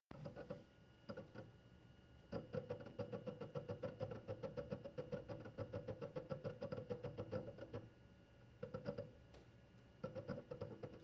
Nun ist es so, dass gerade die zweite, die er eingesetzt hat, sehr starke Klack-Geräusche macht (höre Anhang). Also ich habe mehrere dieser Toshibas verbaut, und höre seltenst so ein lautes, andauerndes Klacken.
Diese Geräusche sind aber schon etwas penetrant und ich empfand sie auch eher fast schon als ungesund klingend.
Anhänge Klackern.ogg 21,2 KB